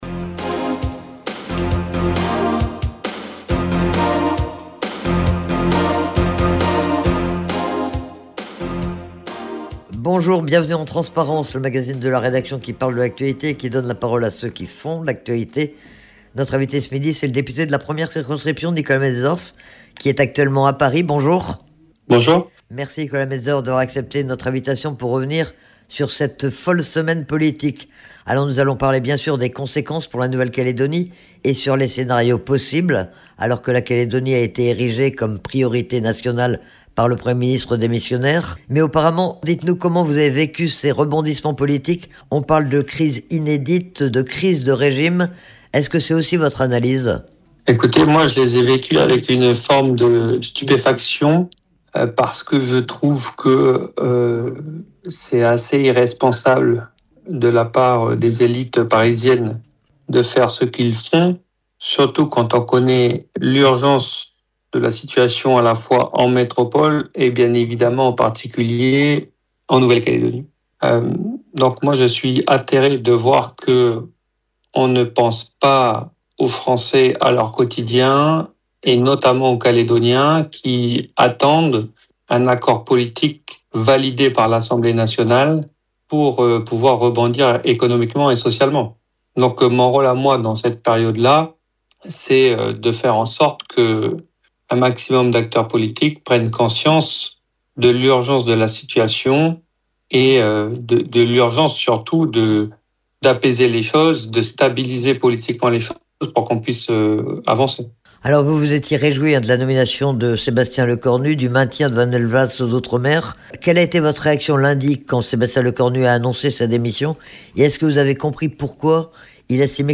en compagnie du député Nicolas Metzdorf qui est interrogé depuis Paris